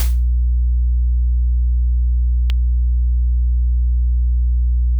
Normalmente hablamos del “flinch” como una vacilación, pero acústicamente, no es una pausa. Es un chasquido. Es la liberación repentina y violenta de tensión cuando la atadura finalmente cede contra el cabrestante.
¿Ese zumbido grave de 60 Hz?
Ese es el motor síncrono haciendo su trabajo, manteniendo el sistema en equilibrio.
Pero exactamente a los 2.5 segundos, ¿esa fractura aguda de alta frecuencia?
¿Notas cómo el zumbido no se detiene después, pero la textura cambia? El “siseo” de la cinta, el ruido de fondo del universo, se vuelve más tenue.